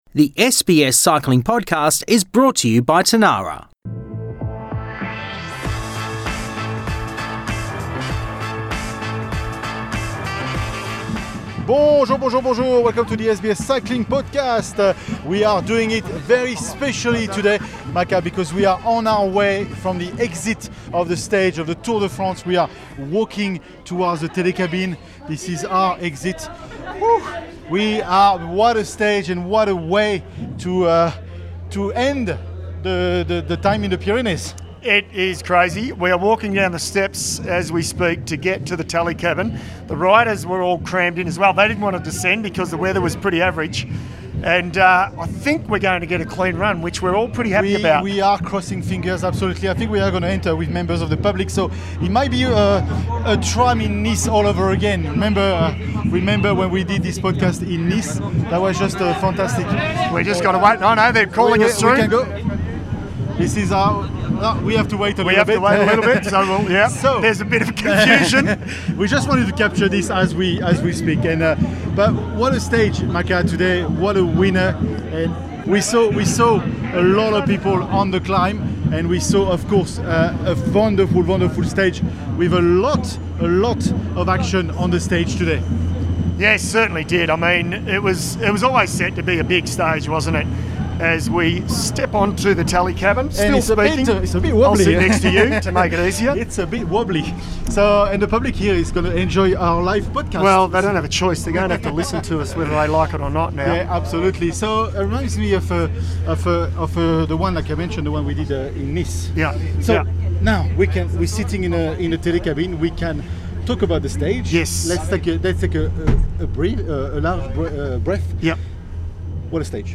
With the buzz of the telecabine ride setting the scene, the discussion centres around a stage full of emotion and action.